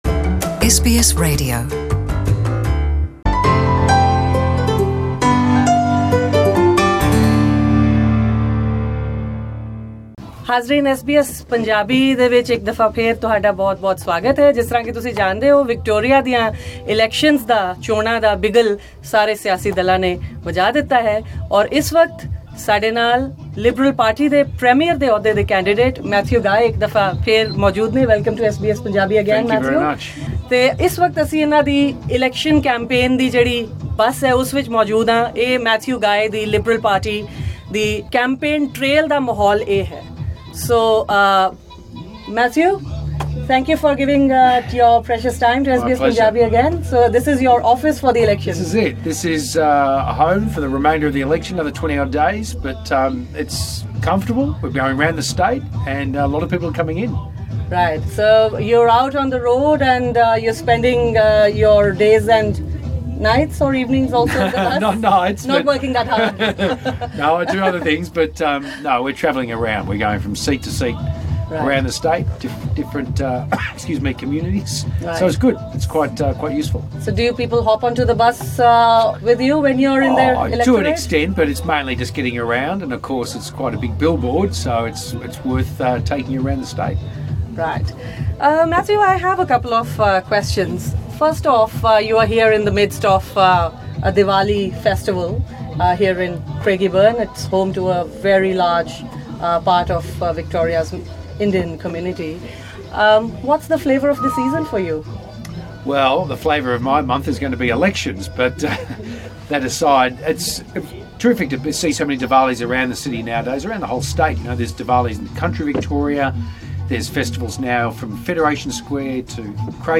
Matthew Guy (L) in an exclusive interview with SBS Punjabi during his election campaign.
In an exclusive interview to SBS Punjabi while on the campaign trail, Mr Guy detailed his party’s topmost issues.